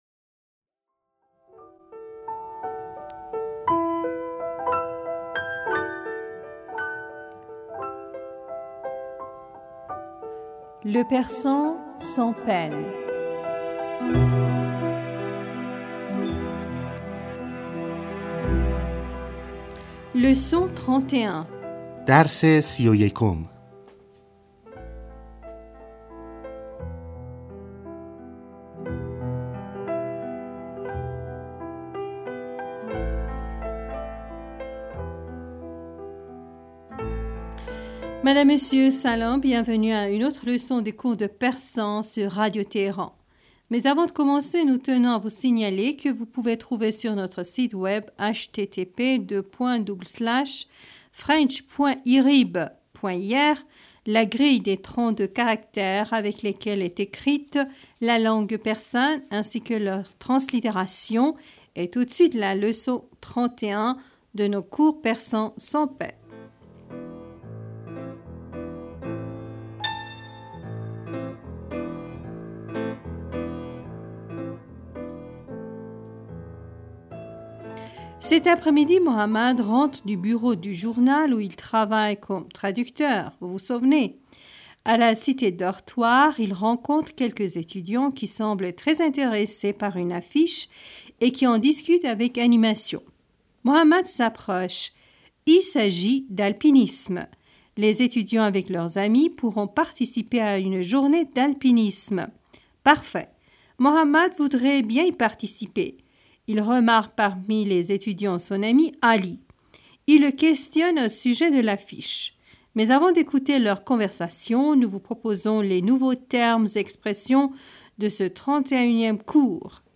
Bienvenus à une autre leçon des cours de persan sur Radio Téhéran.